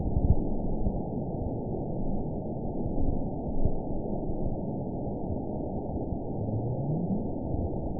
event 919369 date 12/31/23 time 12:58:50 GMT (1 year, 11 months ago) score 9.56 location TSS-AB03 detected by nrw target species NRW annotations +NRW Spectrogram: Frequency (kHz) vs. Time (s) audio not available .wav